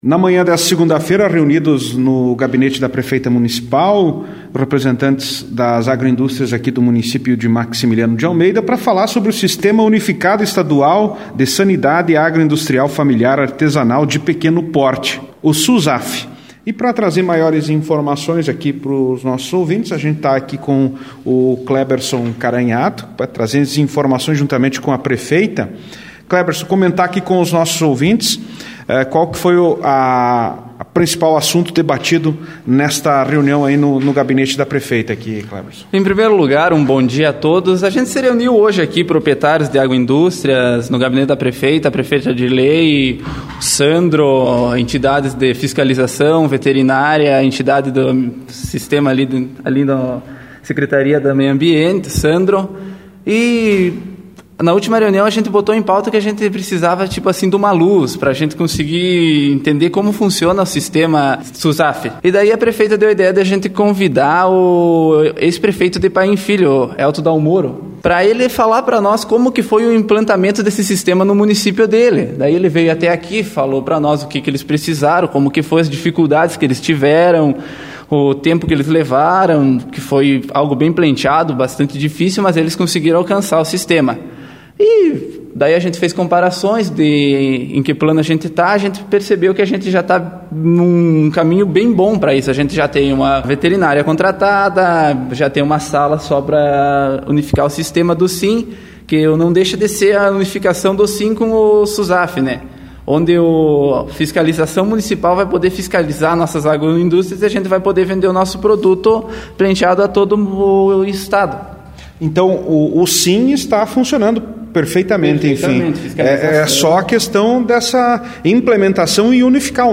Em entrevista à Rádio Interativa a Prefeita Municipal de Maximiliano de Almeida Sra.